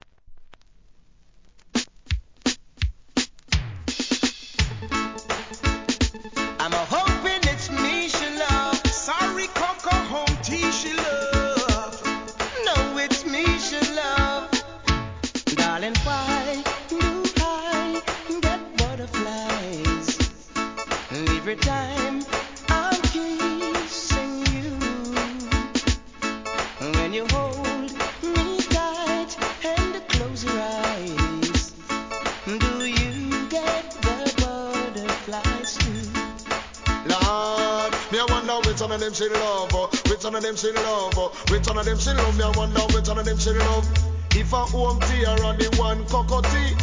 REGGAE
ベースが最高に気持がイイ